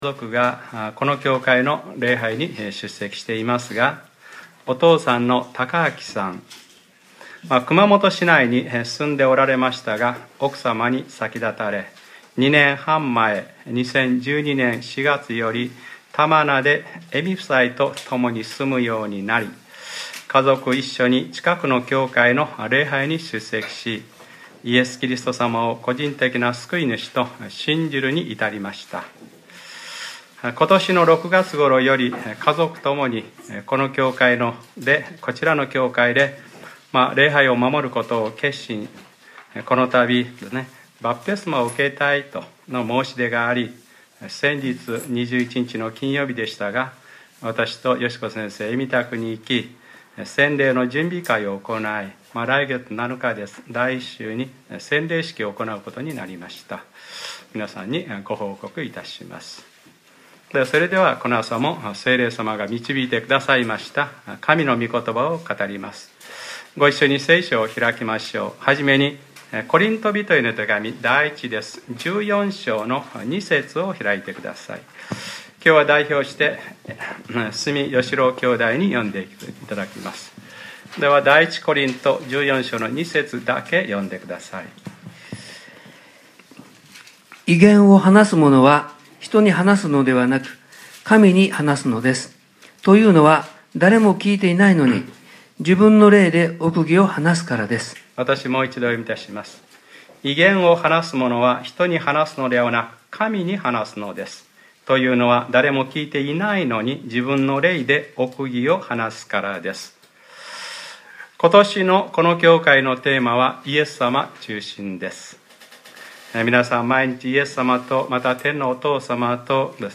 2014年11月23日（日）礼拝説教 『 異言 』